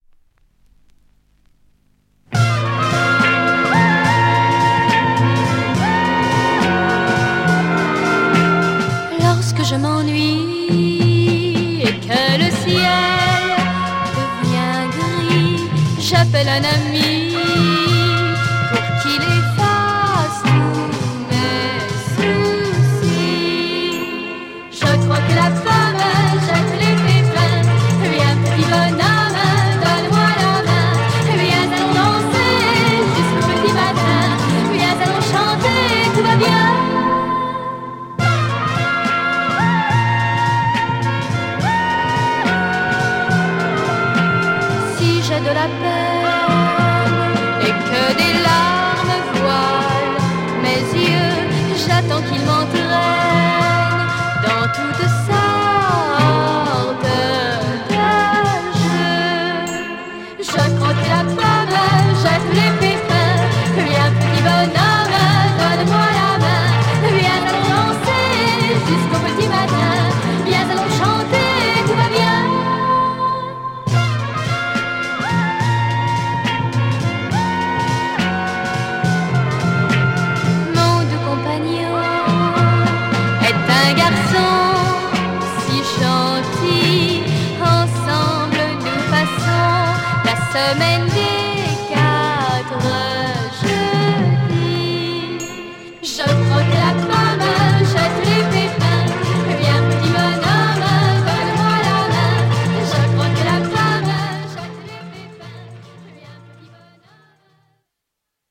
Killer French lolita psych folk 2Siders!